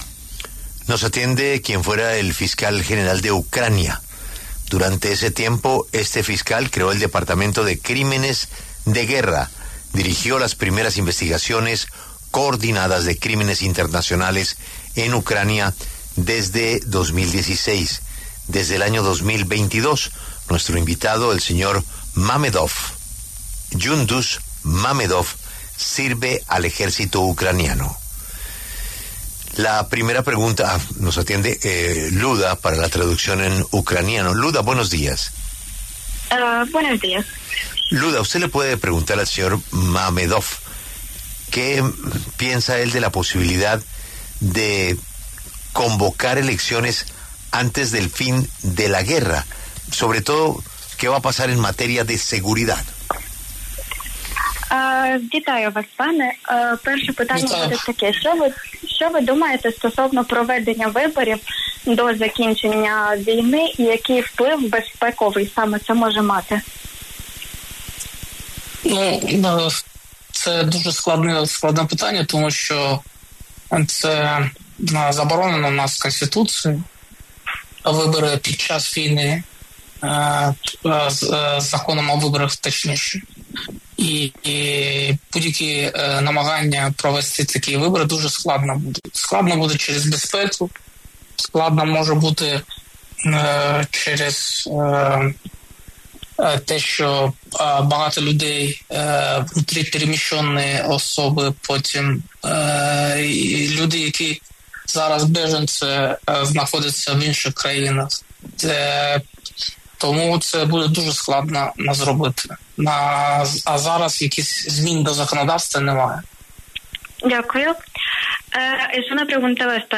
Gyunduz Mamedov, exfiscal general adjunto de Ucrania, conversó con La W sobre el anuncio del presidente Zelenski de considerar la posibilidad de convocar a elecciones en el país.